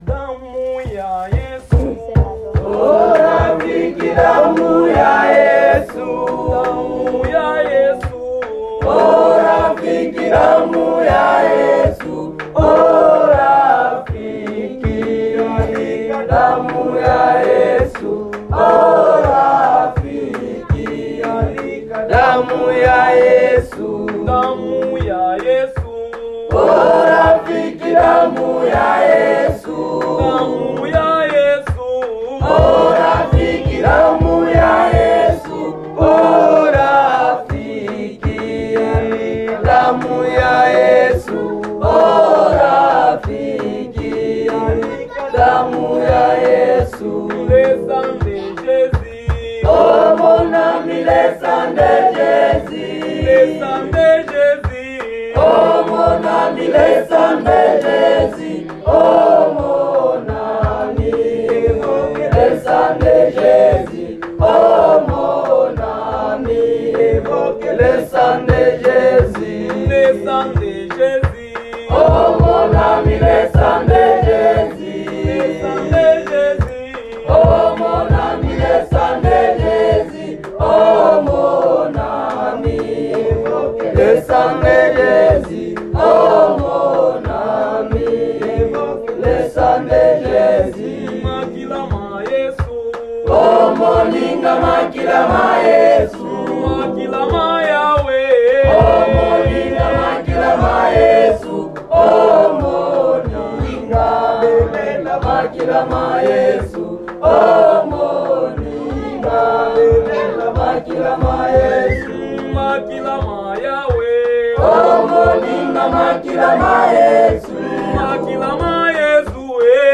This is an open-air structure (pictured at the top) with a tin roof held up by several vertical wooden beams.
The singing and worship take up a ton of time and are similarly congregation-driven. Anyone from the audience with the skill may choose to take the drum, and, instead of a worship team leading from the front like a concert, songs are led by someone among the congregation taking charge either from their seat or stepping into the aisle. Also, most worship songs are call-and-response, and everyone joyfully gets involved. They sing in at least three languages with which everyone in Kitindi is somewhat familiar—Lingala (most commonly spoken in western DRC, not here), Swahili, and French.